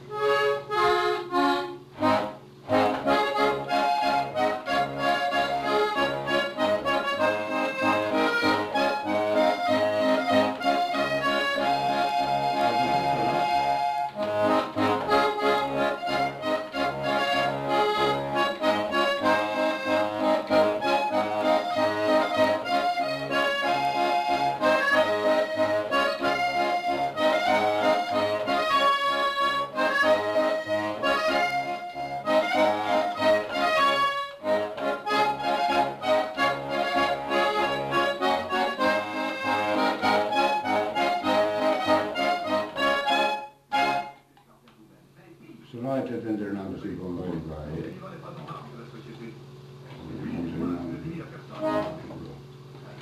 Polka
Genre : morceau instrumental
Instrument de musique : accordéon diatonique
Danse : polka